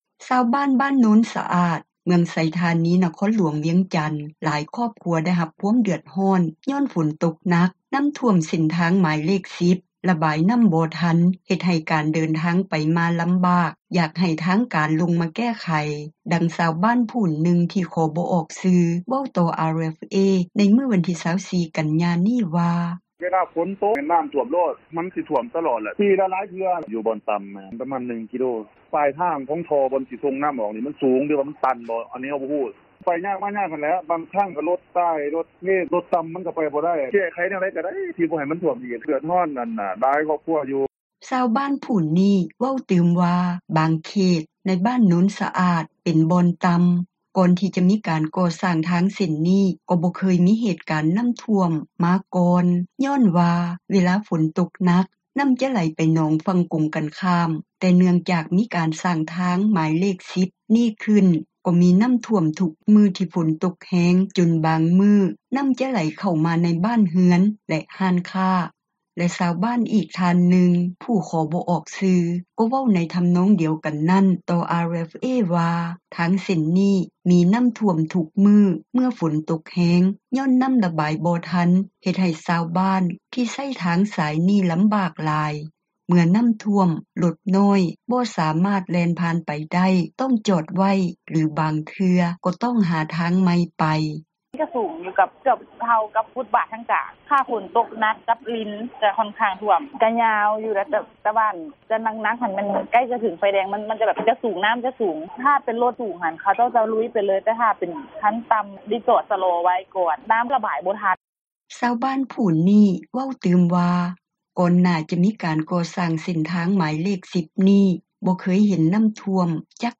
ຊາວບ້ານ ບ້ານໂນນສະອາດ, ເມືອງໄຊທານີ ນະຄອນຫຼວງວຽງຈັນ ຫຼາຍຄອບຄົວ ໄດ້ຮັບຄວາມເດືອດຮ້ອນ ຍ້ອນຝົນຕົກໜັກ ນໍ້າຂັງ ເສັ້ນທາງໝາຍເລຂ 10 ຣະບາຍນໍ້າ ບໍ່ທັນ ເຮັດໃຫ້ ການເດີນທາງ ໄປມາລໍາບາກ, ຢາກໃຫ້ທາງການ ລົງມາແກ້ໄຂ. ດັ່ງຊາວບ້ານ ຜູ້ນຶ່ງ ທີ່ຂໍບໍ່ອອກຊື່ ເວົ້າຕໍ່ RFA ໃນມື້ວັນທີ 24 ກັນຍາ ນີ້ວ່າ: